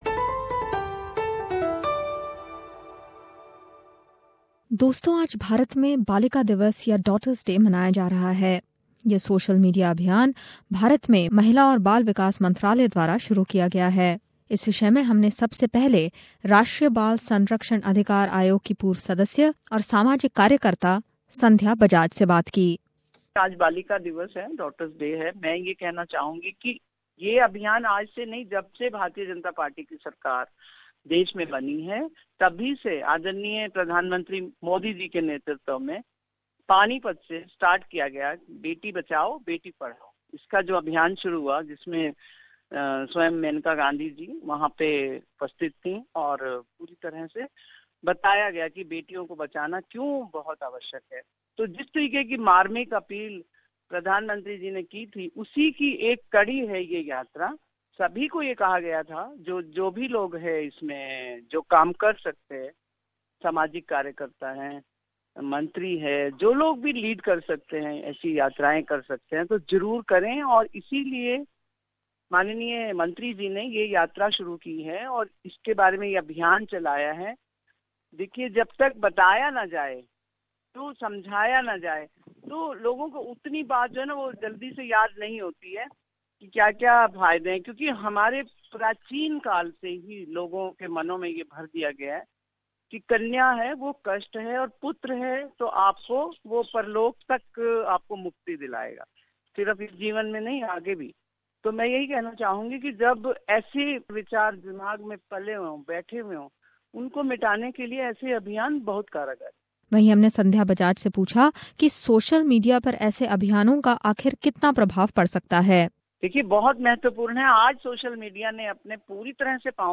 The Ministry of Women and Child Development in India kicked of a social media campaign to celebrate Daughter's Day today and celebrate this week as Daughters week. Tune in for this report.